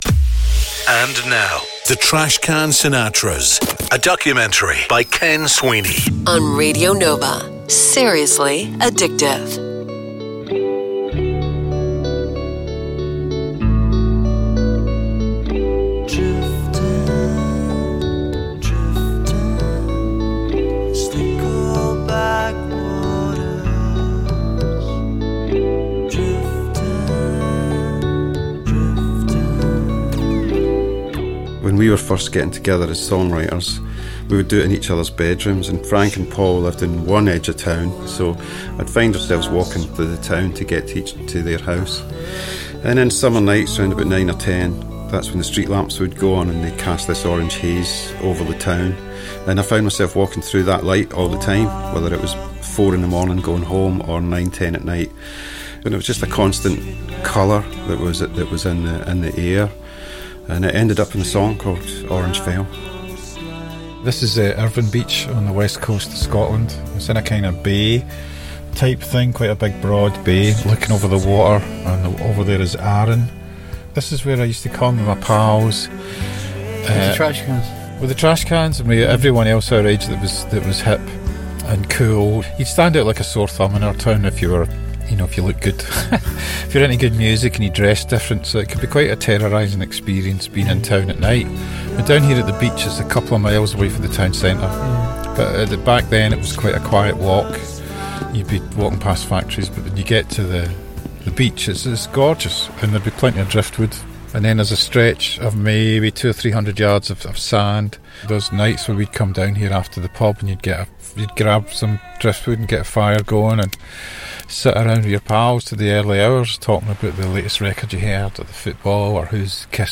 Coming up this Sunday on Radio Nova, a new documentary will be aired focusing on the Scottish band, The Trashcan Sinatras.